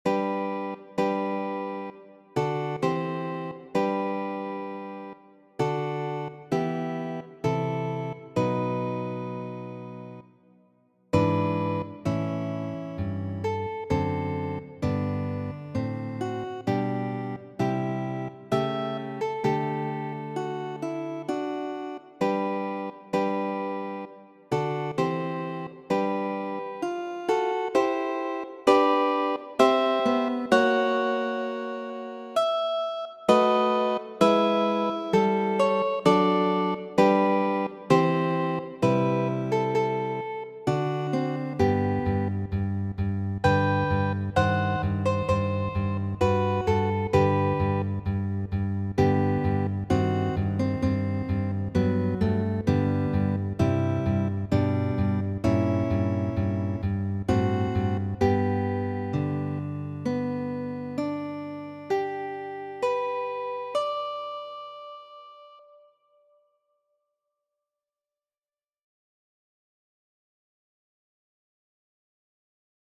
Muziko:
Matenpreĝo, de Petro Ludoviko Ĉejkovski, aranĝita por du gitaroj.